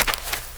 Index of /90_sSampleCDs/AKAI S6000 CD-ROM - Volume 6/Human/FOOTSTEPS_2
DIRT 3.WAV